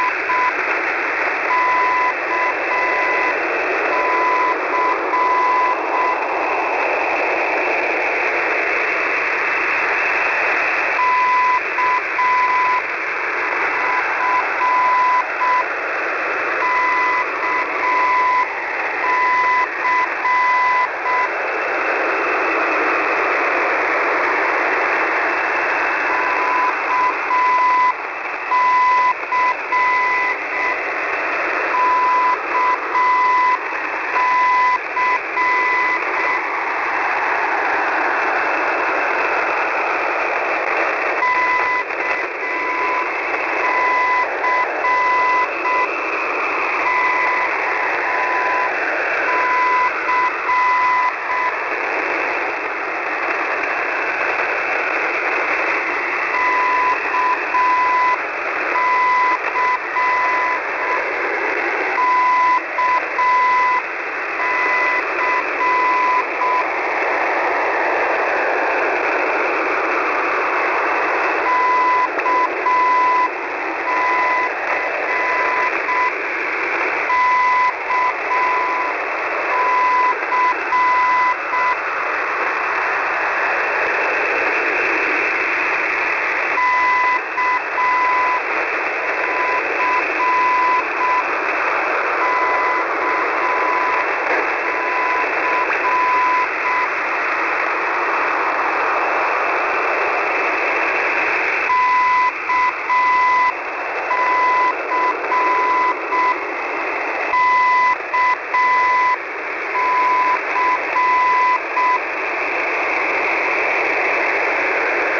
Mode: CW